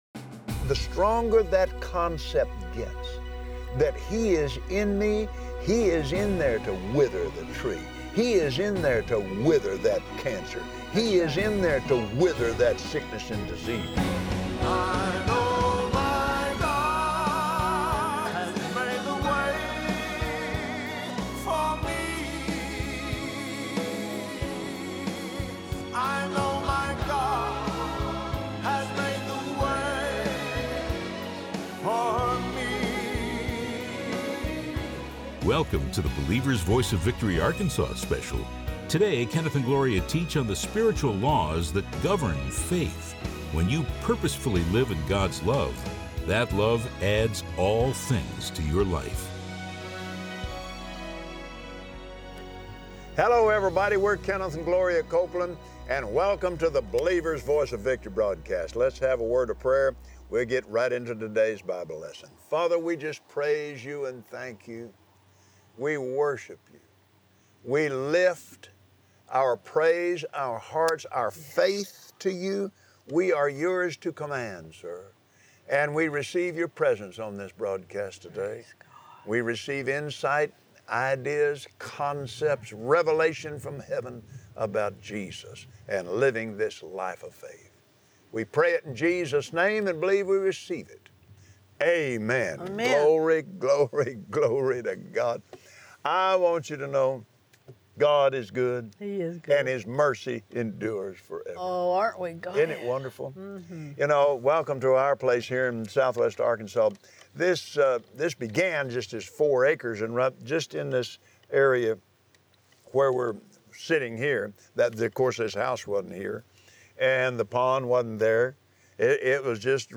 Believers Voice of Victory Audio Broadcast for Wednesday 06/28/2017 God loves you and He is for you. Watch Kenneth and Gloria Copeland on Believer’s Voice of Victory share how God is Love, and He is always on your side.